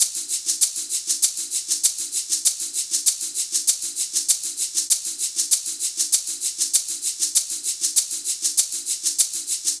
Shaker 02.wav